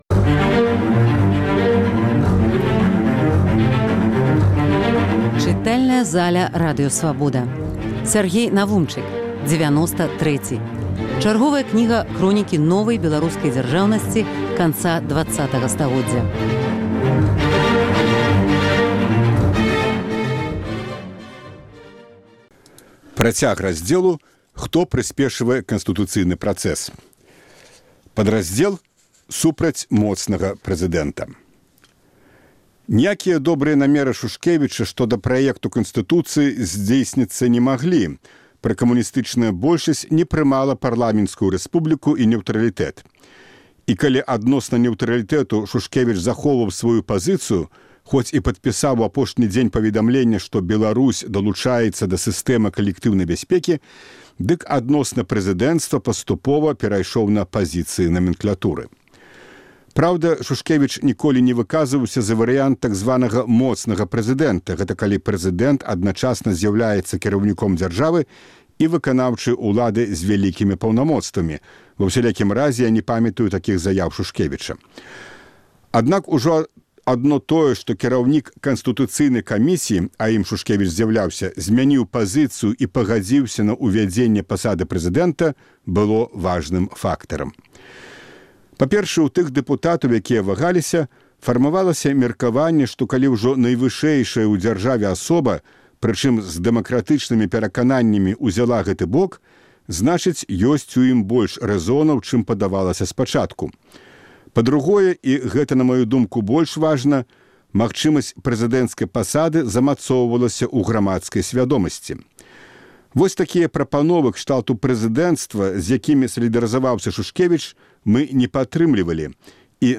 Фрагмэнты кнігі Сяргея Навумчыка "Дзевяноста трэці". Чытае аўтар.